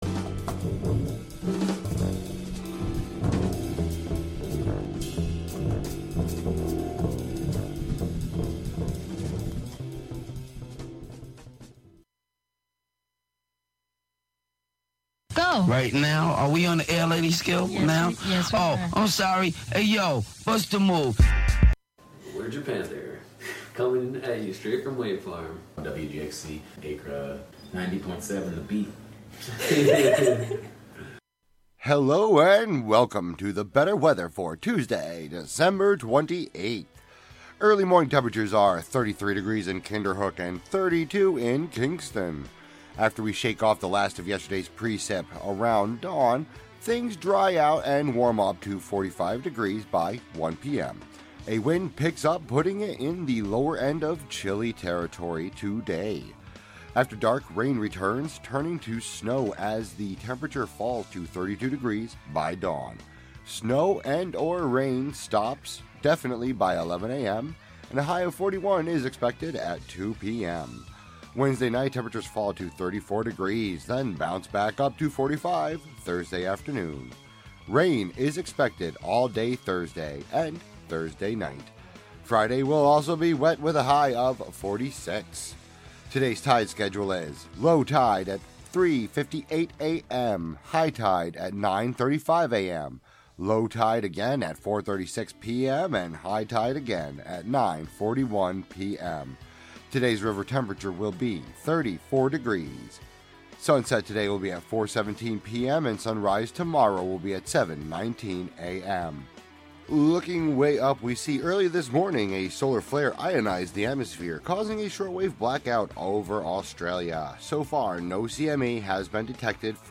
Broadcast from Catskill.